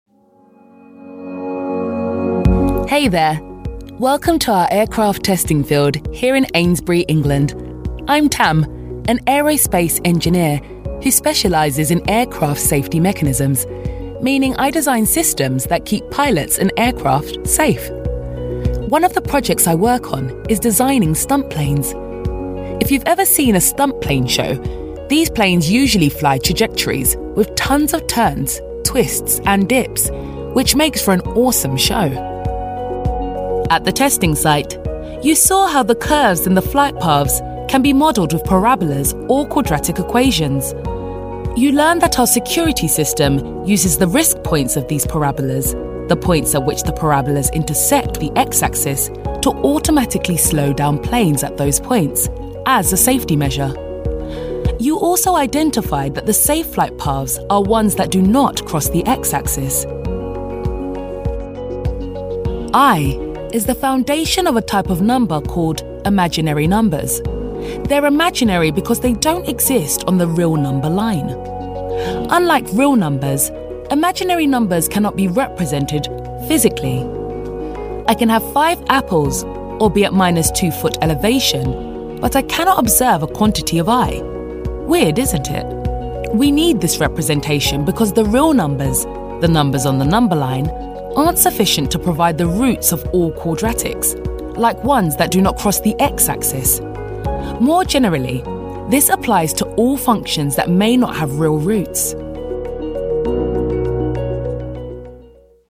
Engels (Brits)
Warm, Commercieel, Diep, Vriendelijk, Zakelijk
E-learning